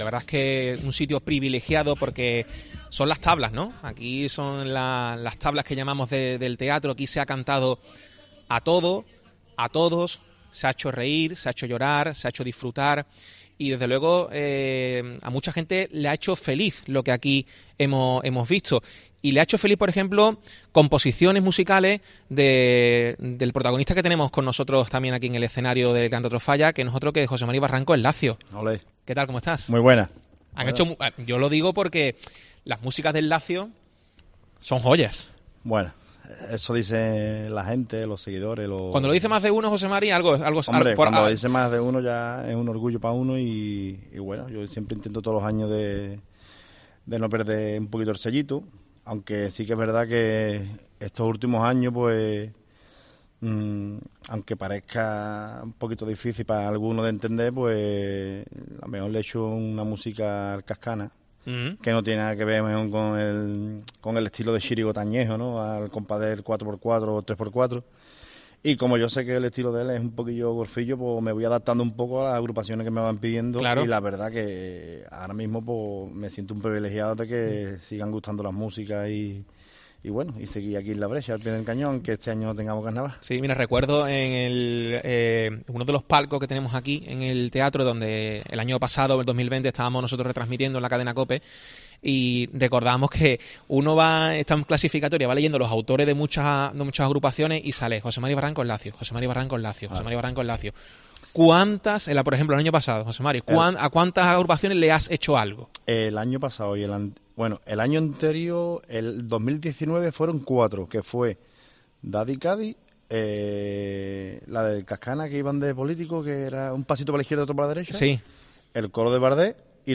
ha estado en los micrófonos de COPE desde el Gran Teatro Falla